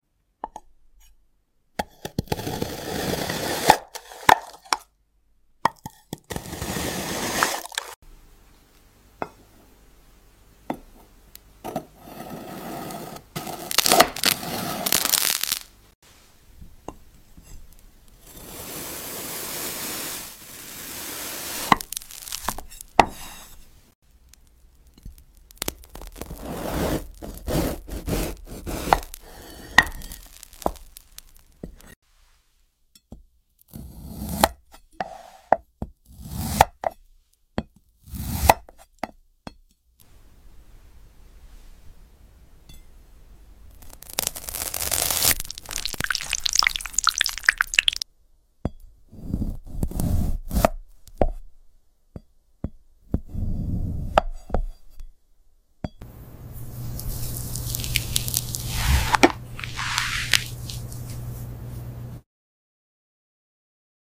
(Sn): Tin sings when it’s cut — seriously. 🎤 Slice smooth, hear the squeal, and don’t miss the singing bean can.